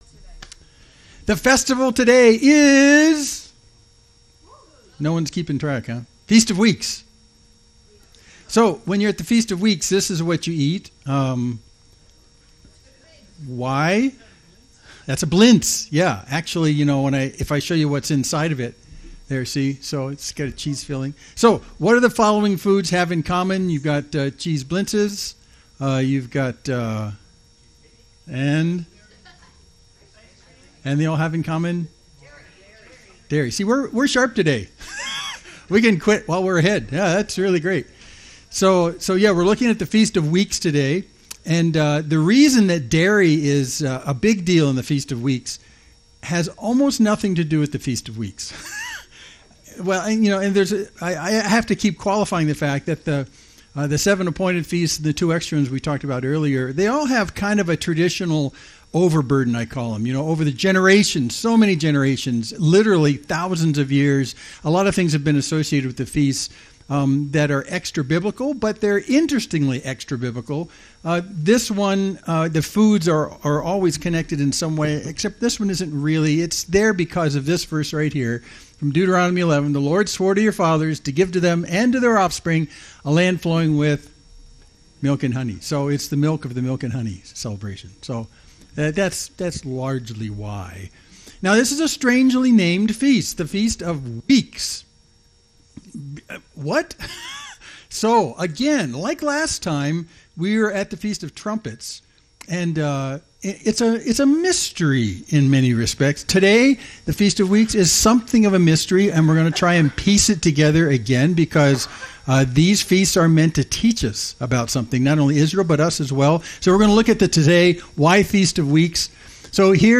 Main Street Church Sermon (17.10 - )